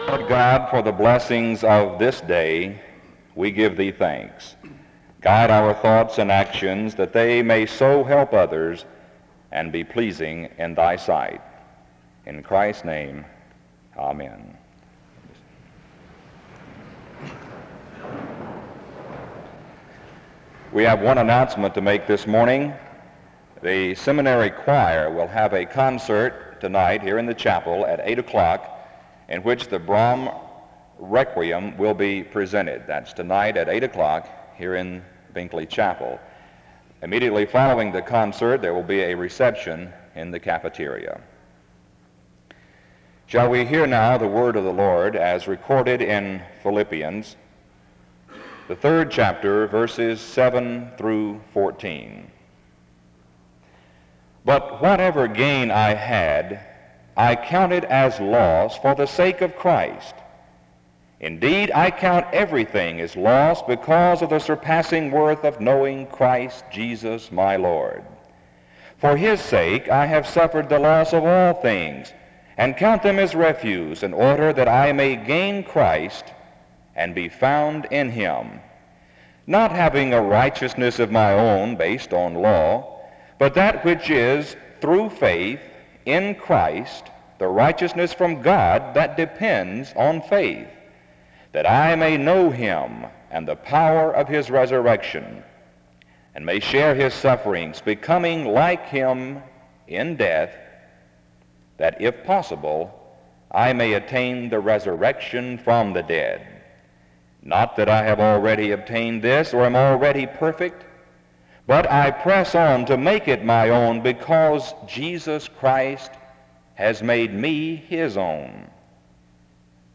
The service begins with prayer and announcements (0:00-0:41). Afterwards, the speaker reads Philippians 3:7-14 (0:42-2:24).
He closes in prayer (24:38-25:37).